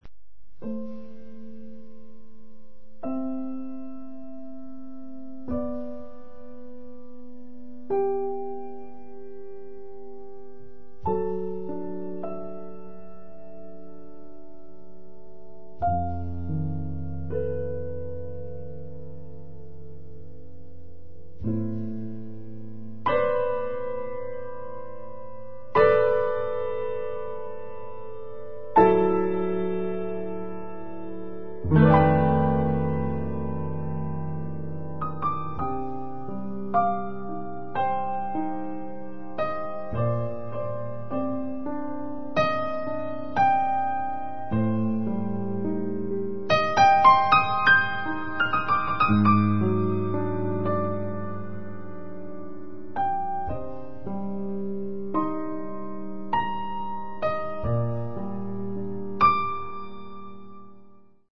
pianoforte
sassofoni